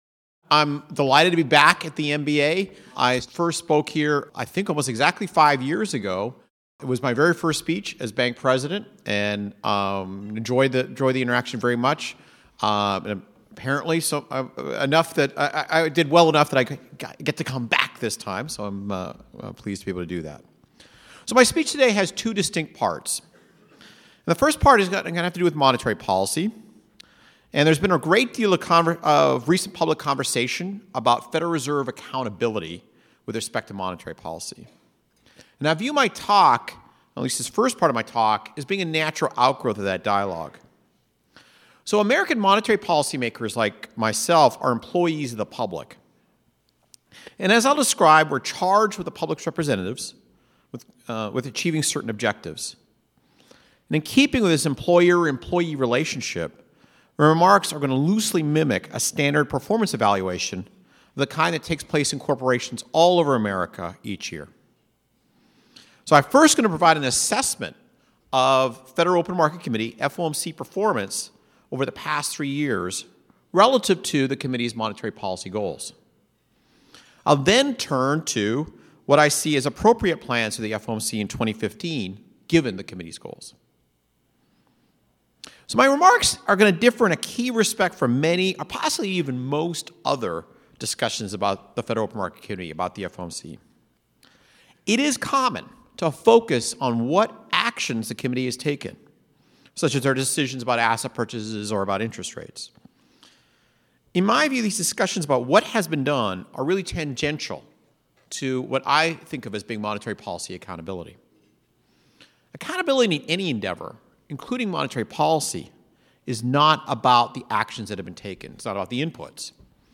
Speech ( video ) Media QA ( video ) Media Q&A ( audio ) Speech ( audio ) Note 1 Thanks for the introduction, and thank you for the invitation to join you here today.